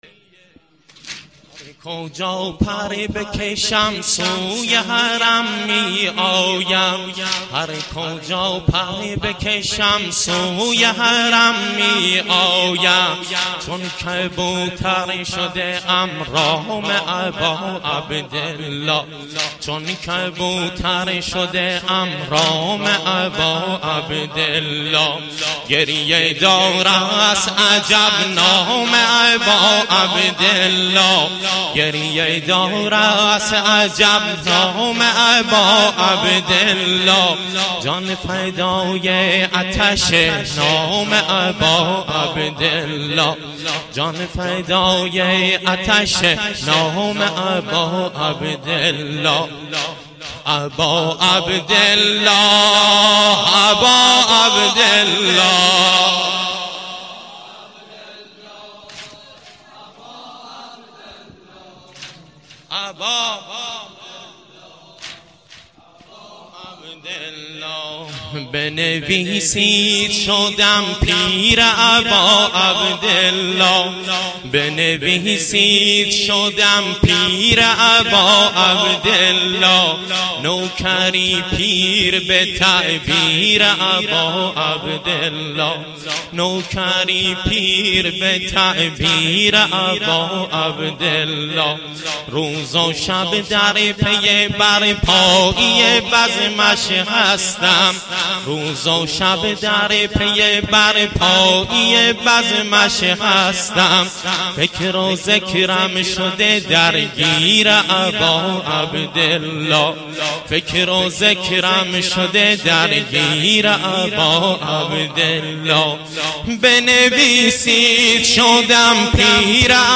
واحد - هر کجا پر بکشم سوی - مداح